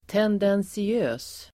Ladda ner uttalet
Uttal: [tendensi'ö:s]